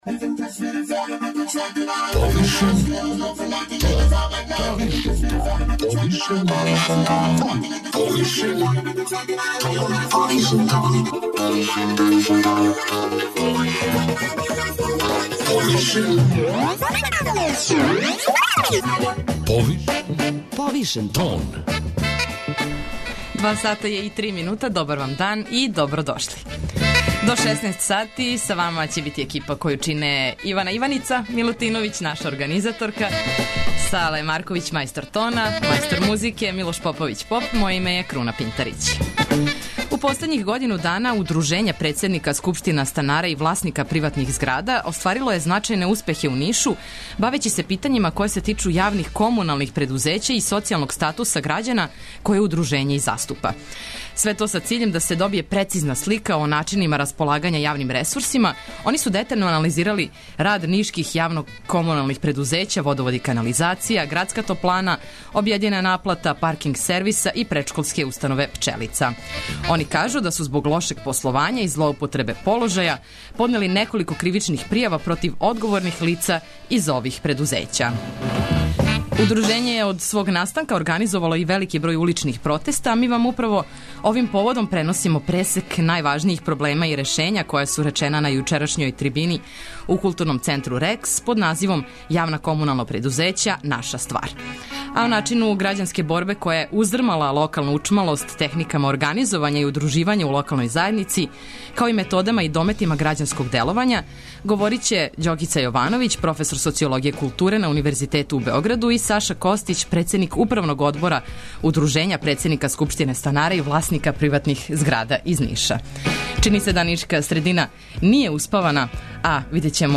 Ми вам управо овим поводом преносимо пресек најважнијих проблема и решења која су речена на јучерашњој трибини у КЦ „Rex", под називом „